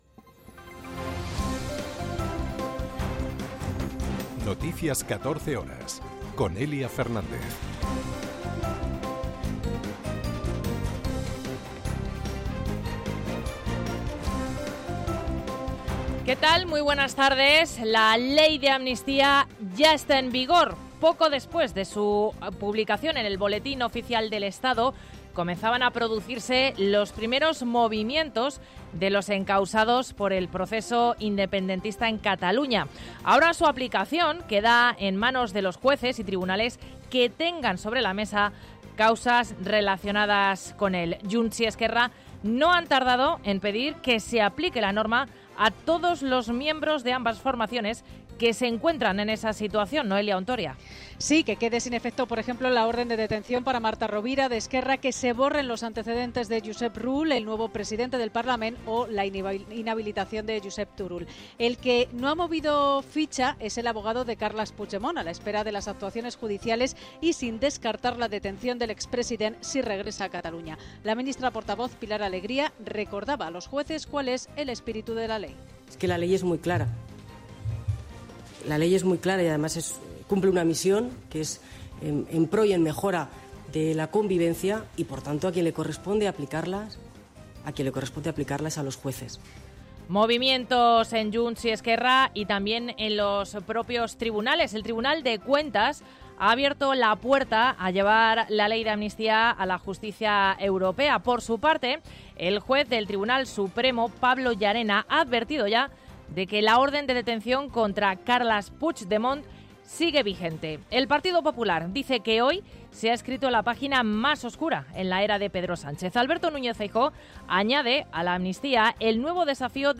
Noticias 14 horas 11.06.2024